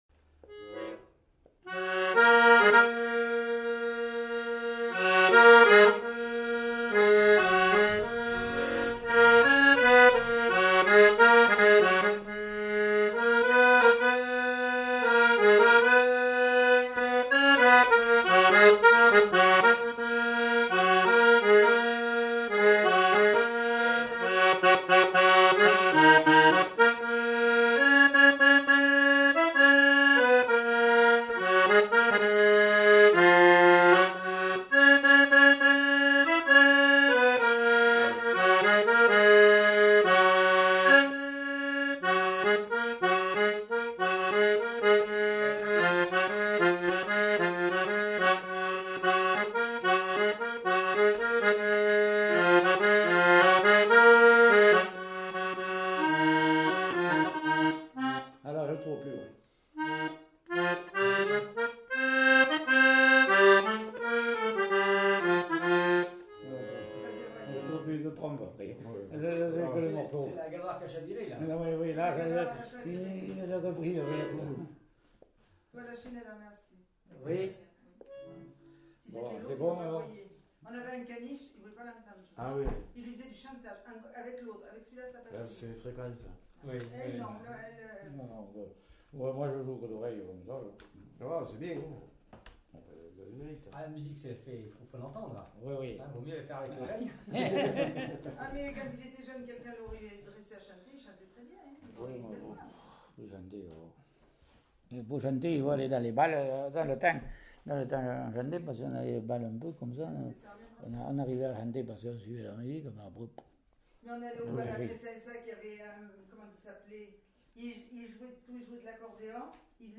Aire culturelle : Quercy
Lieu : Vayrac
Genre : morceau instrumental
Instrument de musique : accordéon chromatique
Danse : valse